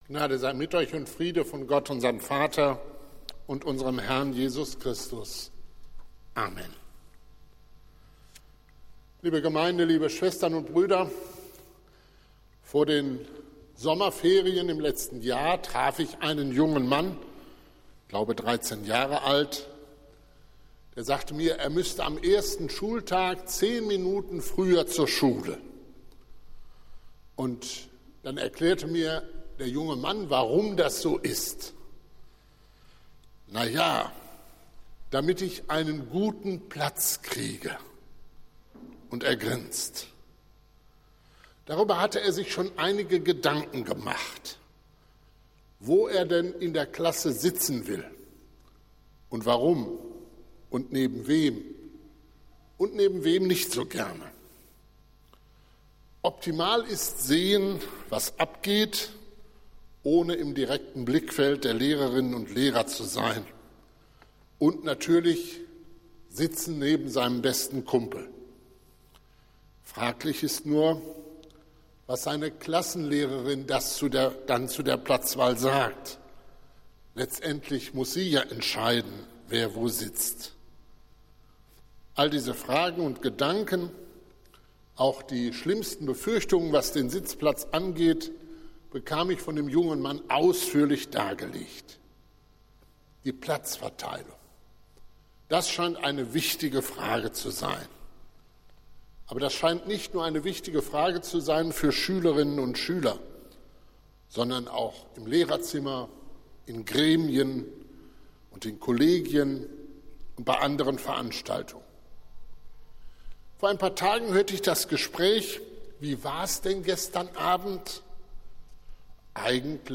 Predigt des Gottesdienstes aus der Zionskirche vom Sonntag, 03.04.2022
Wir haben uns daher in Absprache mit der Zionskirche entschlossen, die Predigten zum Nachhören anzubieten.